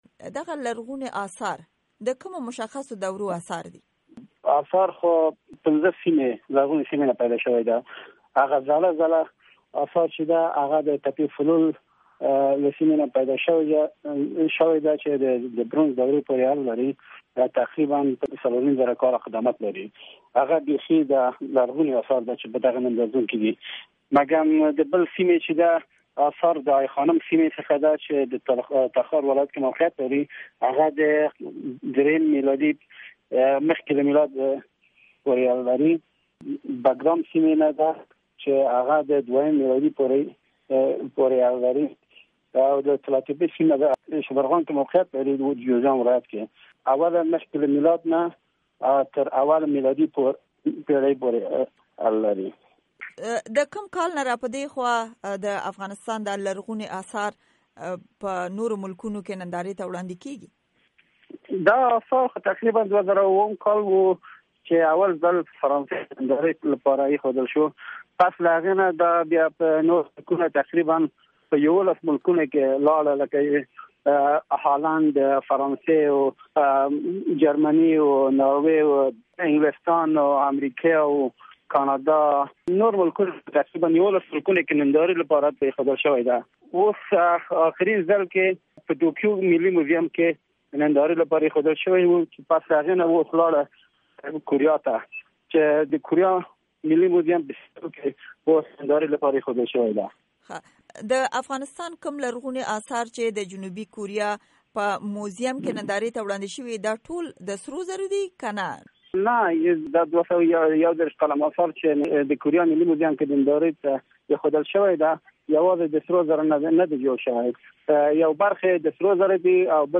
مرکې
د افغانستان د ملي موزيم ريس فهيم رحيمي سره مرکه